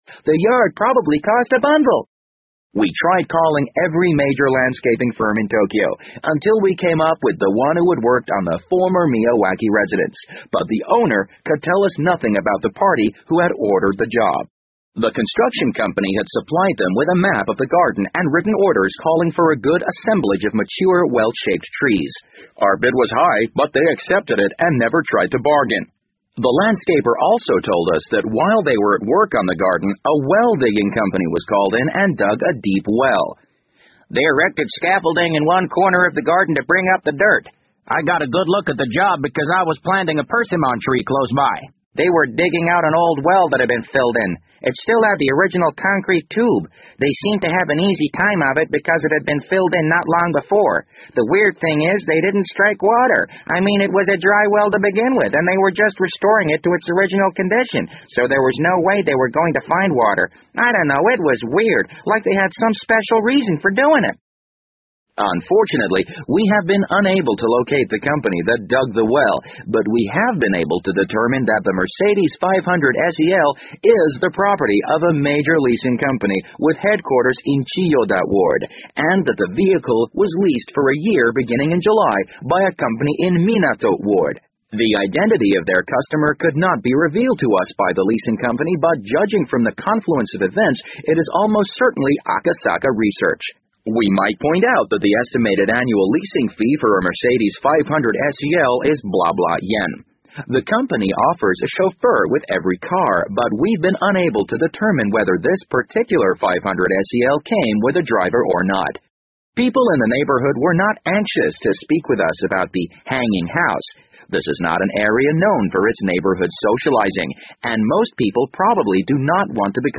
BBC英文广播剧在线听 The Wind Up Bird 010 - 9 听力文件下载—在线英语听力室